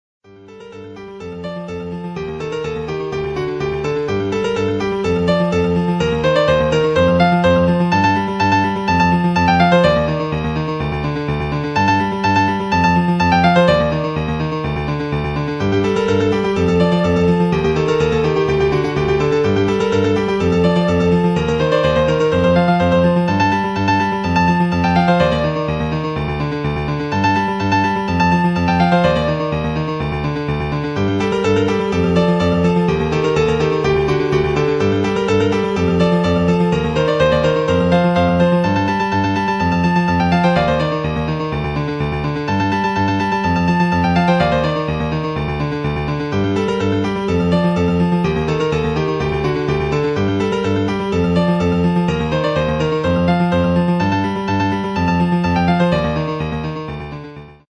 Ringtone
main theme